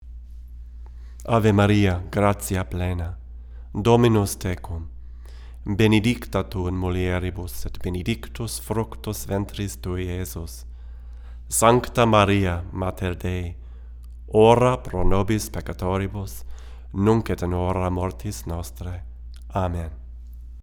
Here are several quick, 1-take MP3 sound files to give you an idea of what to expect. These MP3 files have no compression, EQ or reverb -- just straight signal, tracked with this Rode NT1-A mic into a TAB-Funkenwerk V78M preamp using a Sony PCM DI flash recorder.
VOICE-OVER / VOCAL